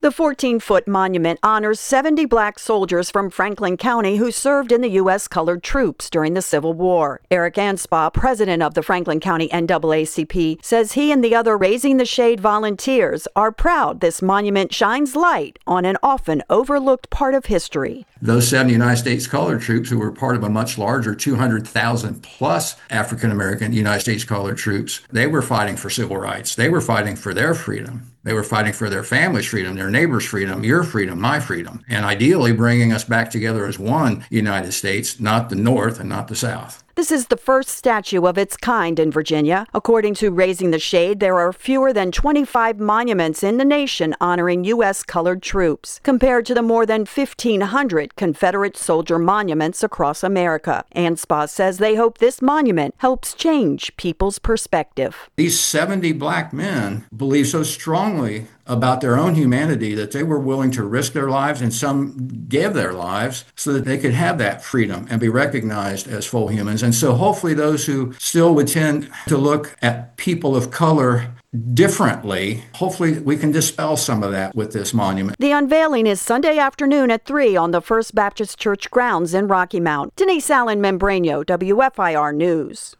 1-16-Statue-Unveiling-wrap-2.mp3